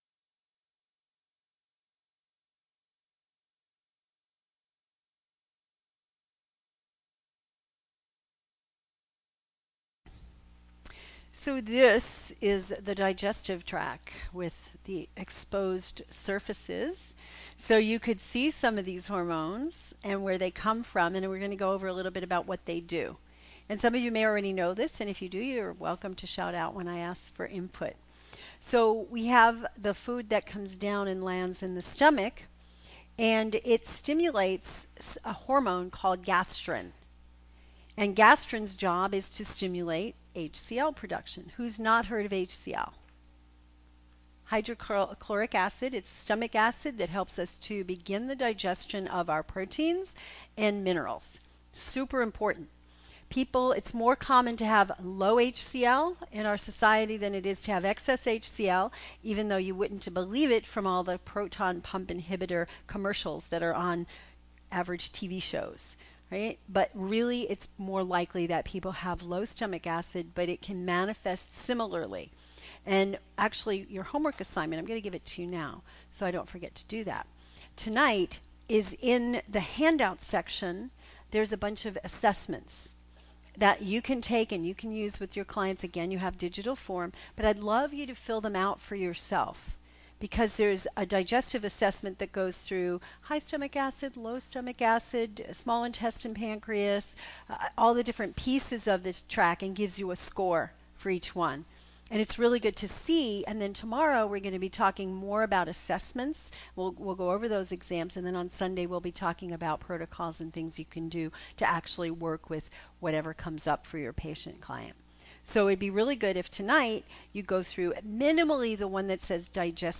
The presentation below is an excerpt from the SHINE 2015 Conference.